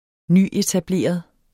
Udtale [ -etaˌbleˀʌð ]